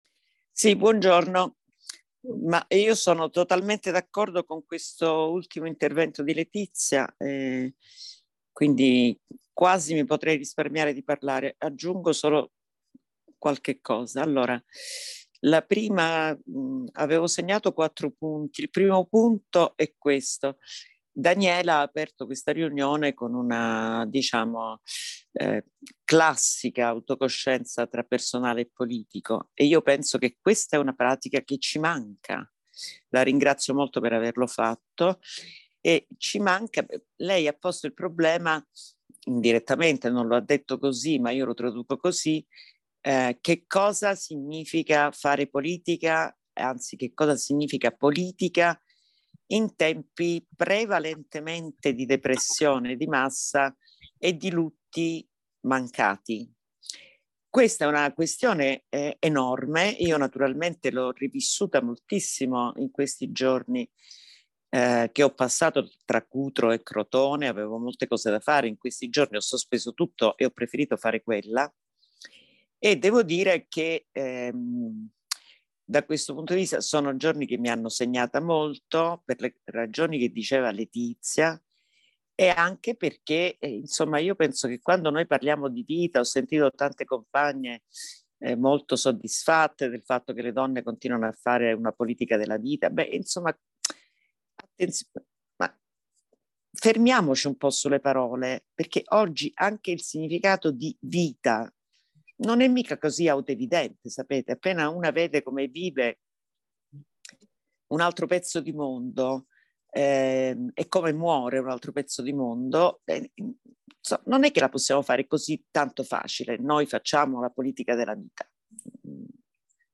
Intervento audio